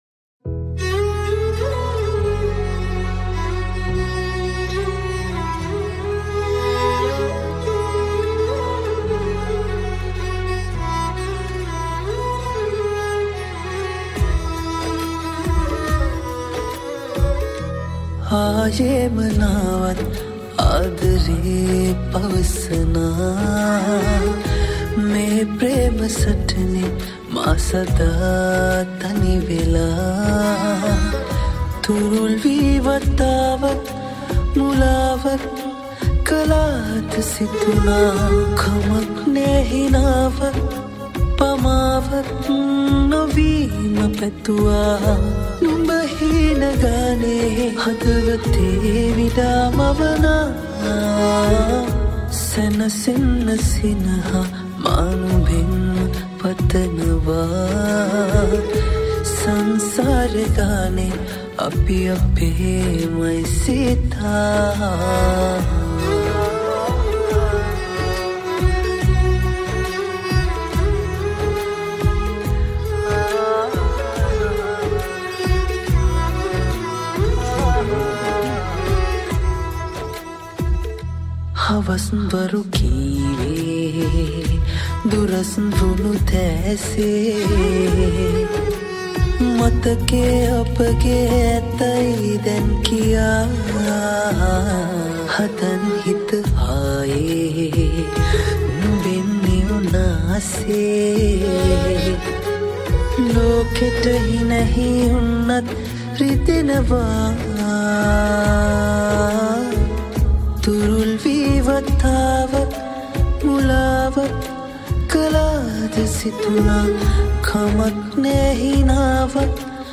Esraj